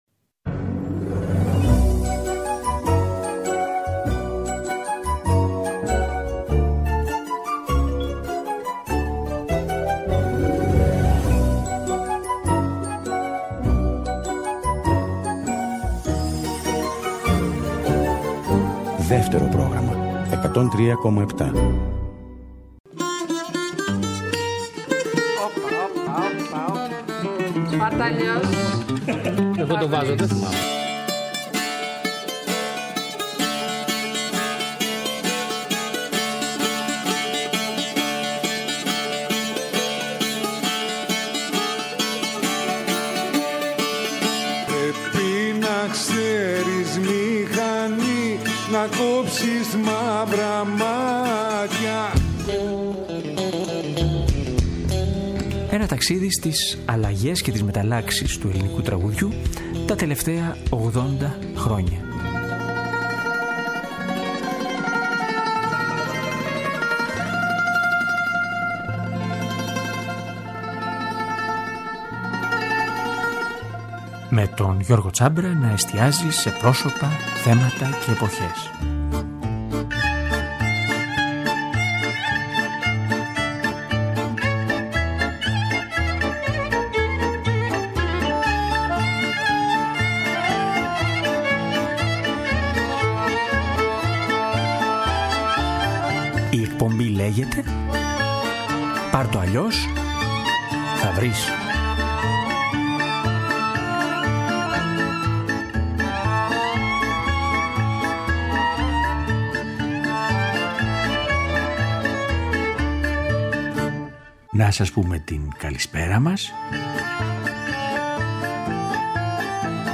τόσο τρυφερό και χαμηλόφωνο
προπολεμικά ρεμπέτικα